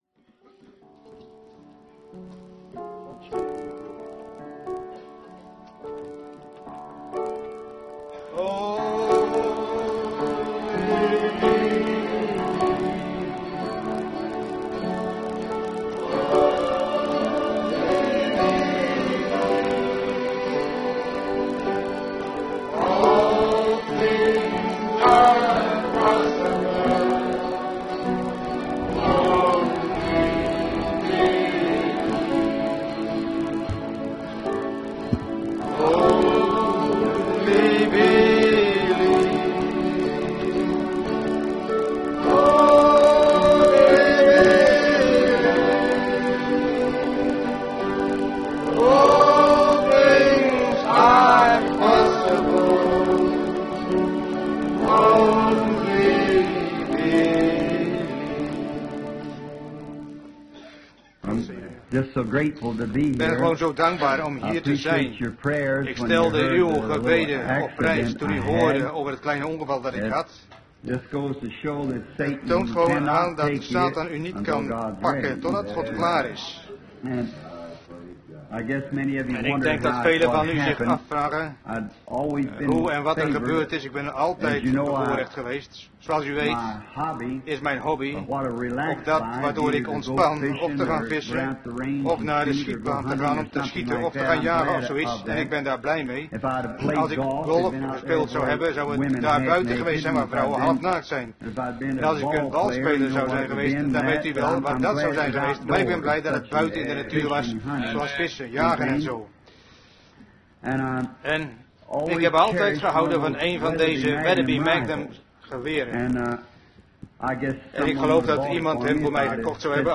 Prediking